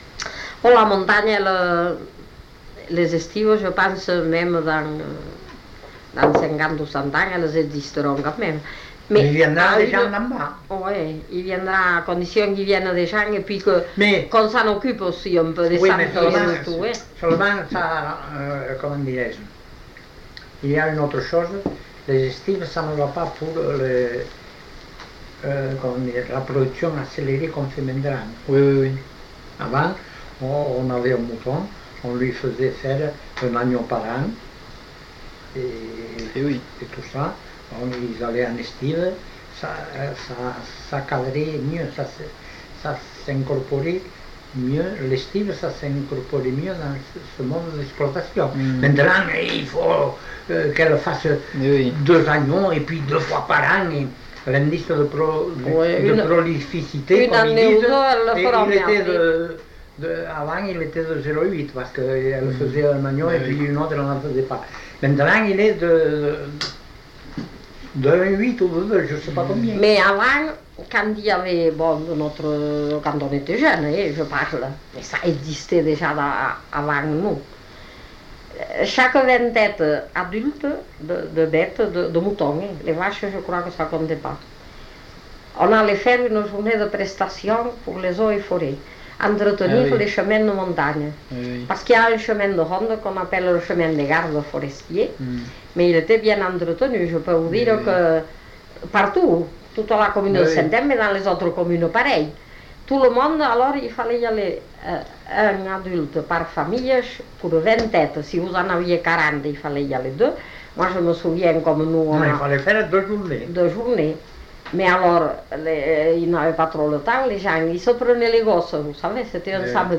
Lieu : Eylie (lieu-dit)
Genre : témoignage thématique